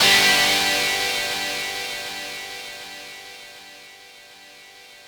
ChordG.wav